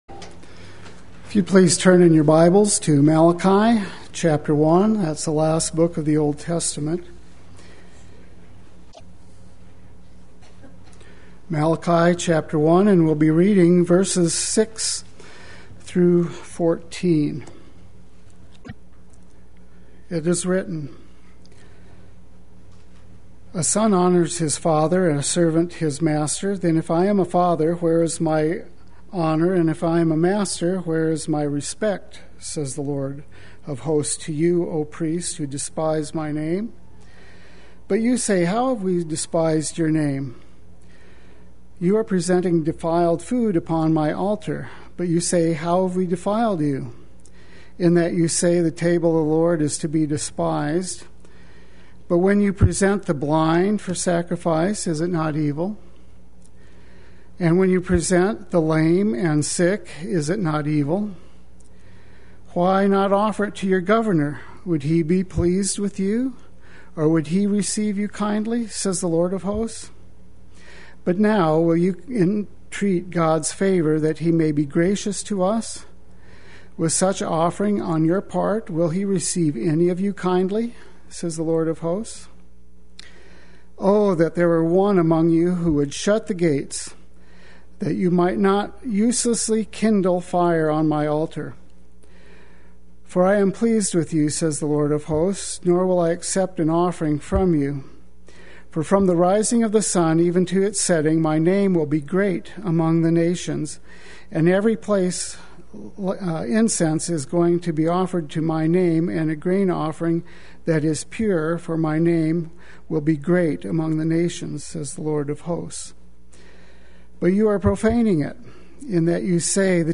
Play Sermon Get HCF Teaching Automatically.
Do We Despise God’s Name Wednesday Worship